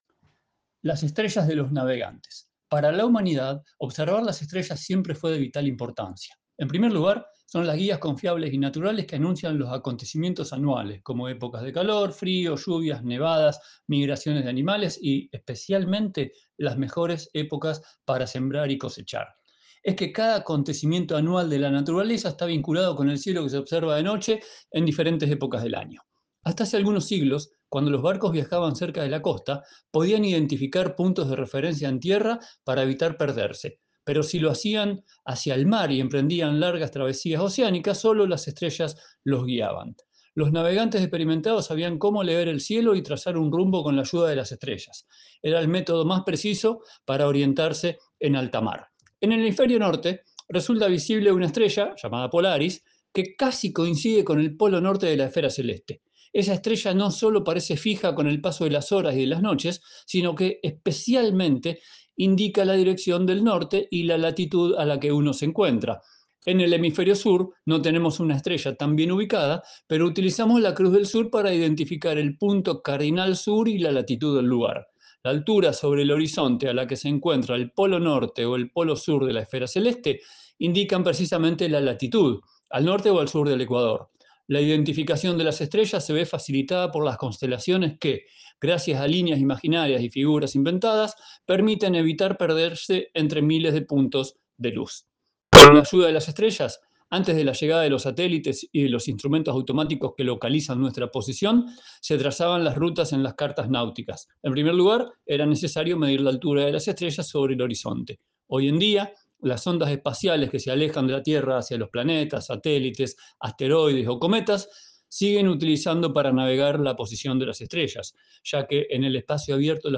Il progetto “Idiomi celesti” promuove l’osservazione ad occhio nudo del cielo stellato con testi scritti e letti ad alta voce in tutte le lingue, dialetti compresi, come ad esempio la lingua ladina e il dialetto lumezzanese.